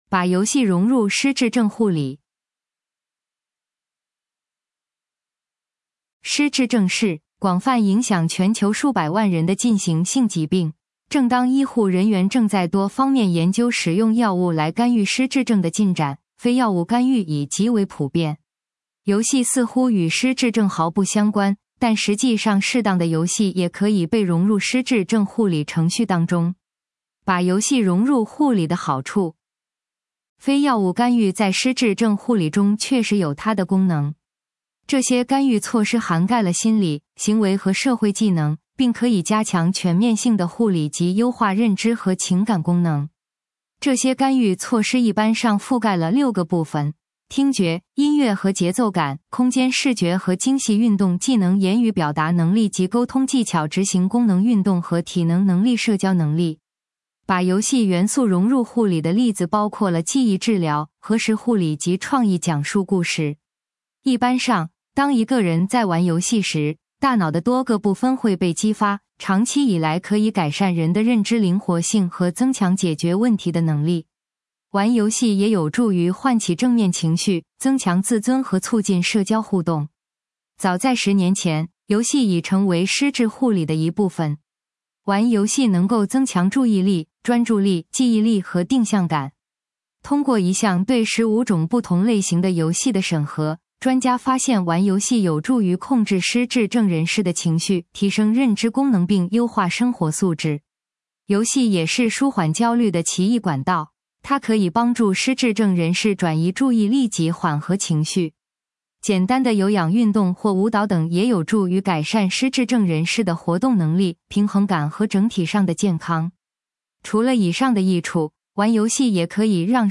文章朗读